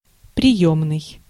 Ääntäminen
Translitterointi: prijomnyi.